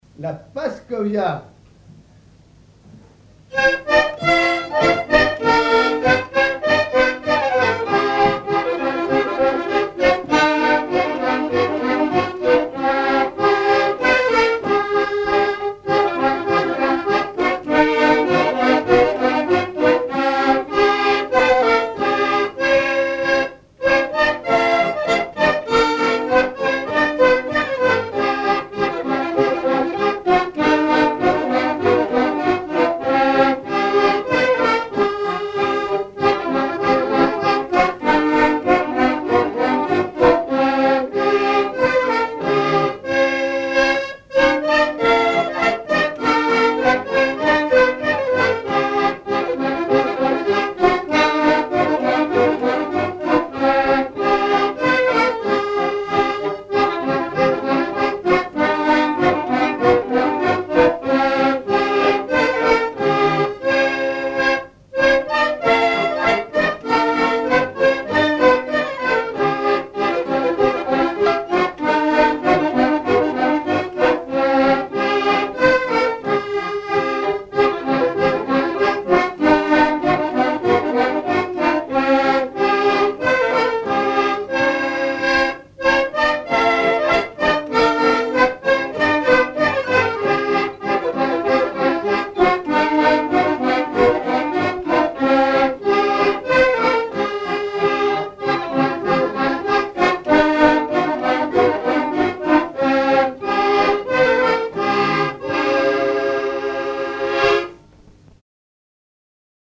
Rochetrejoux
danse : paskovia
Pièce musicale inédite